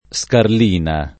scarlina [ S karl & na ]